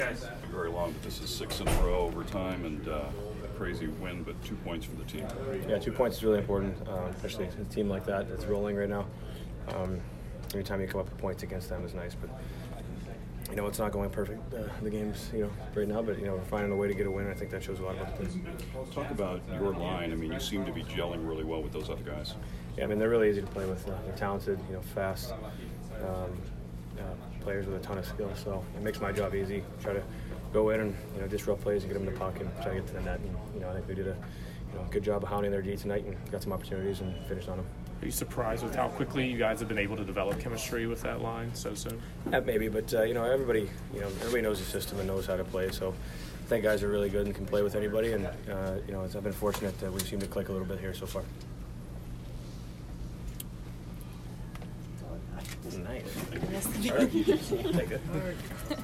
J.T. Miller post-game 3/6